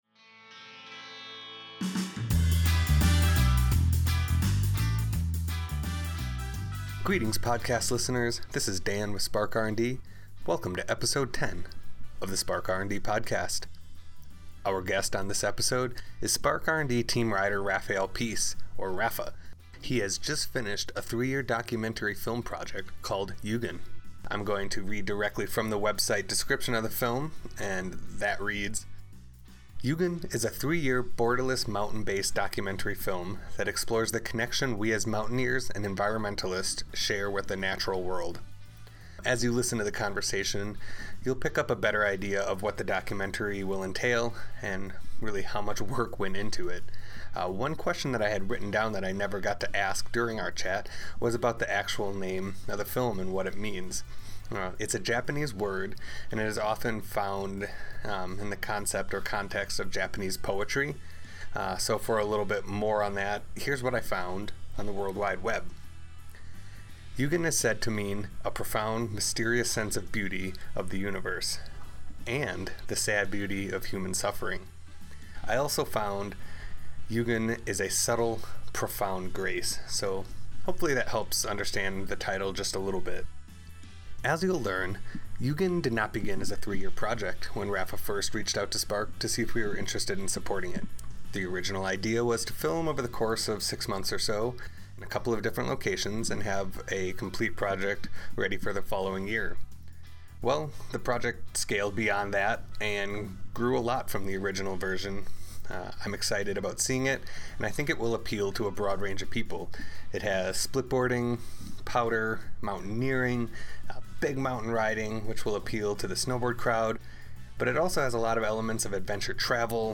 Episode 10 - An Interview